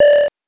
powerup_18.wav